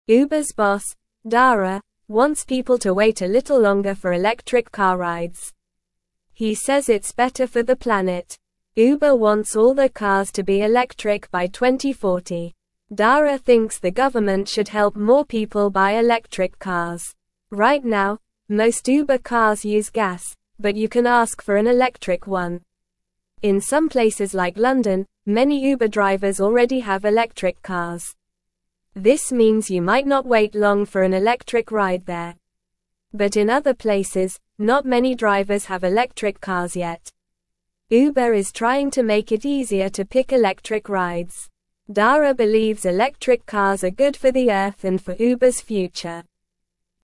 Normal
English-Newsroom-Beginner-NORMAL-Reading-Uber-Boss-Wants-More-Electric-Cars-for-Rides.mp3